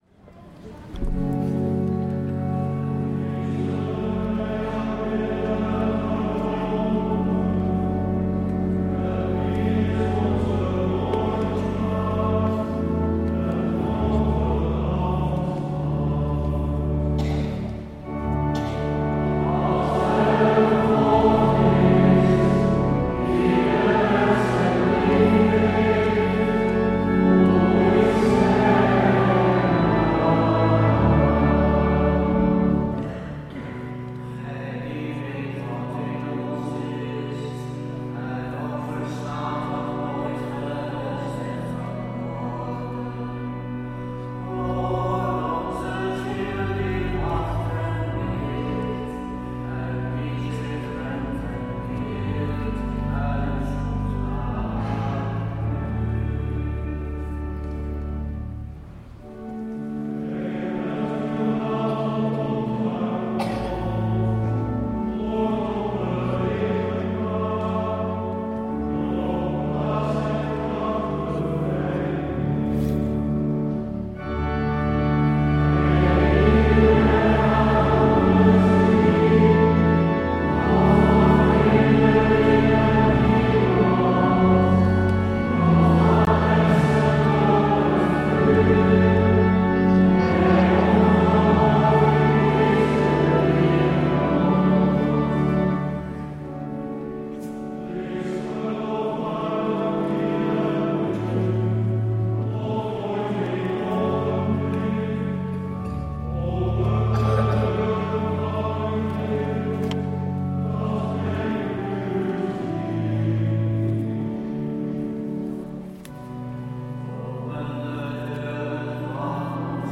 Dominicus Kerk, Amsterdam: organ, choir and congregation